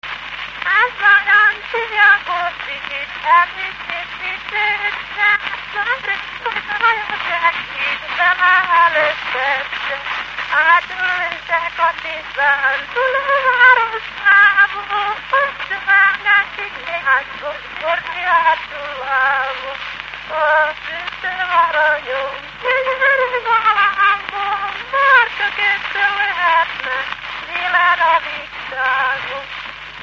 Dunántúl - Zala vm. - Kerkaszentmihályfa
Stílus: 6. Duda-kanász mulattató stílus
Kadencia: 5 (1) 5 1